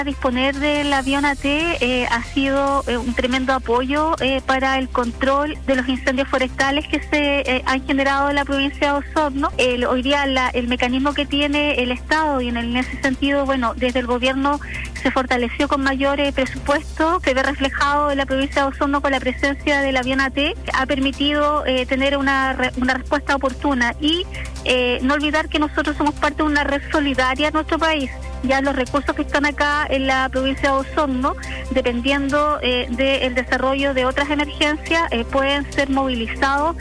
A propósito del incendio forestal ocurrido en la localidad de Pucatrihue, La Radio conversó con la delegada presidencial provincial de Osorno, Claudia Pailalef, quien comentó el análisis de la emergencia.